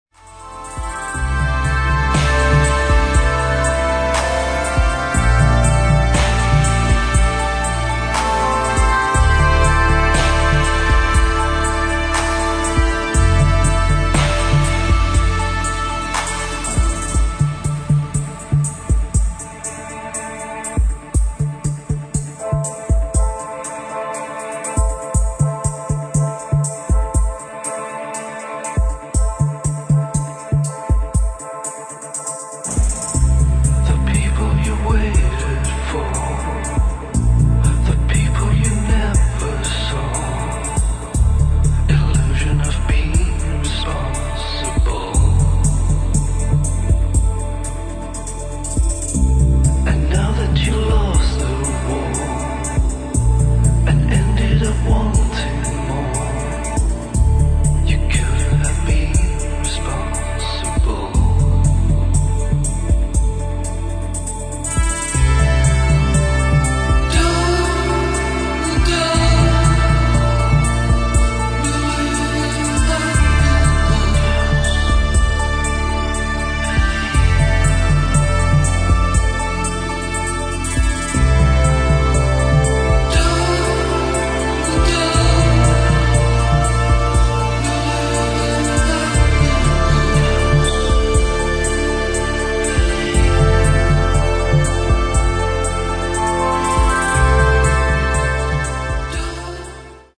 [ HOUSE / ELECTRONIC ]